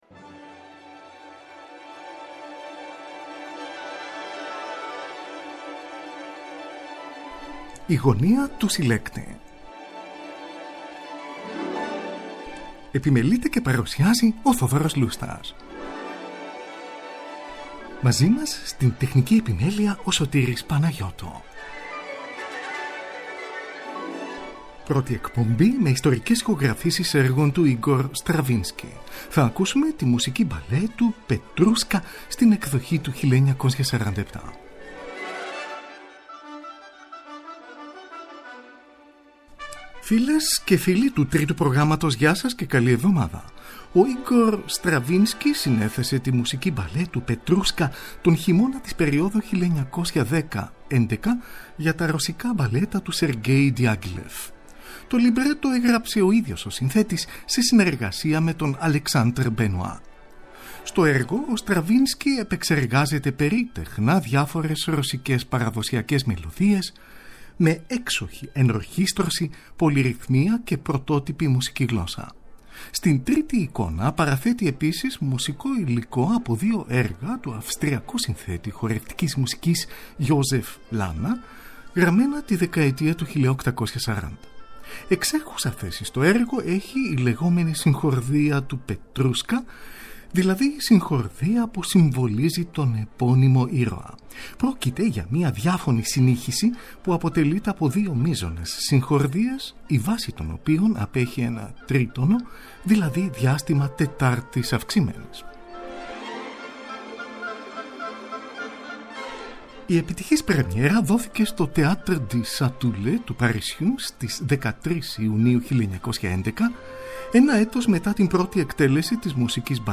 Ιστορικές Ηχογραφήσεις
Μουσική Μπαλέτου
από ηχογράφηση του Μαρτίου 1967 σε studio